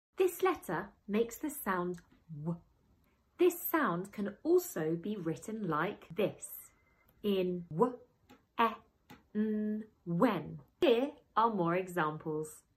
W and WH make the same sound. Here are some examples of when it is used in words.